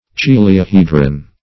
Search Result for " chiliahedron" : The Collaborative International Dictionary of English v.0.48: Chiliahedron \Chil"i*a*hedron\, n. [Gr. chi`lioi a thousand + ? base, fr. ? to sit.]
chiliahedron.mp3